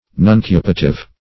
Nuncupative \Nun*cu"pa*tive\, a. [L. nuncupativus nominal: cf.
nuncupative.mp3